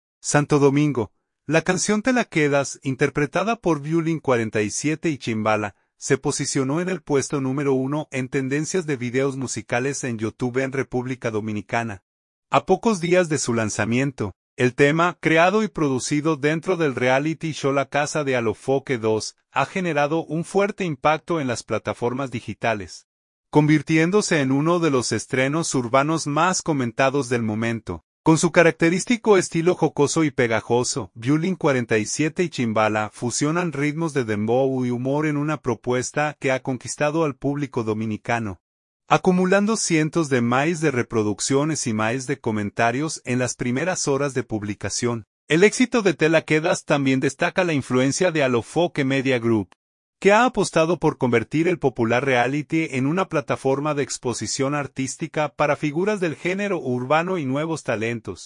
ritmos de dembow